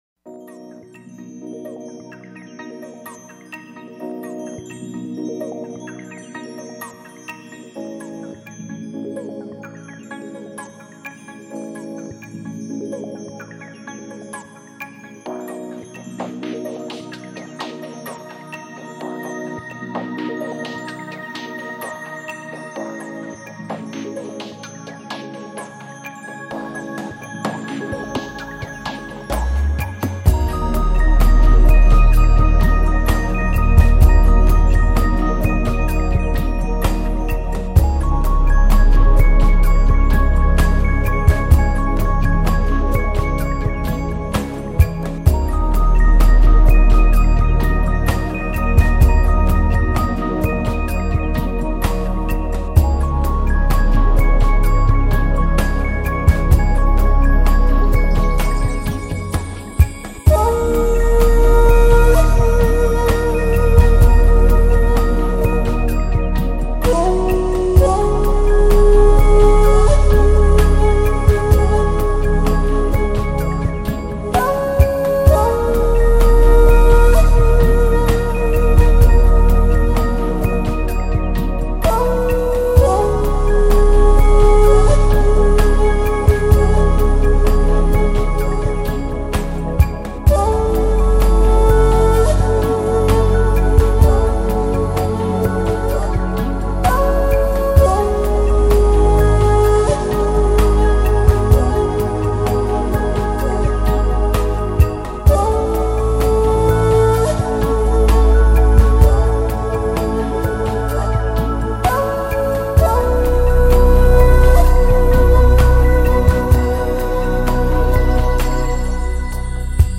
舒缓平和的音乐可以引导听众平衡及和谐心灵情绪。